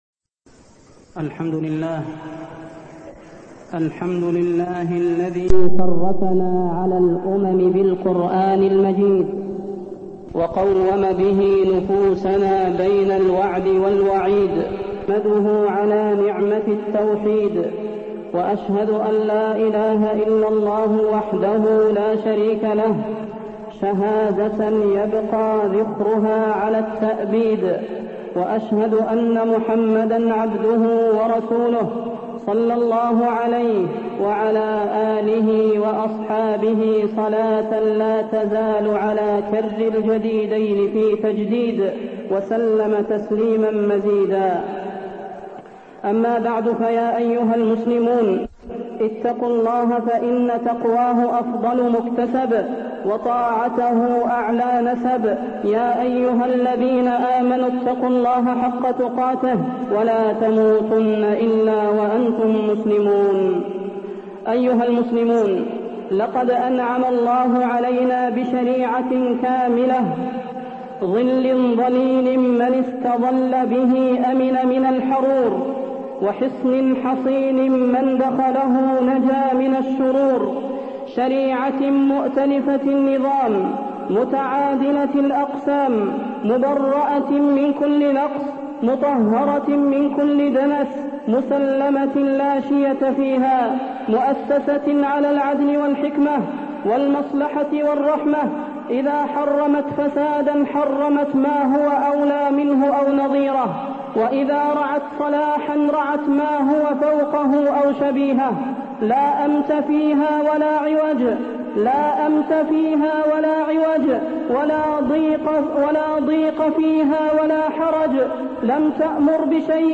تاريخ النشر ٢٠ شوال ١٤٢٢ هـ المكان: المسجد النبوي الشيخ: فضيلة الشيخ د. صلاح بن محمد البدير فضيلة الشيخ د. صلاح بن محمد البدير وجوب تبليغ العلم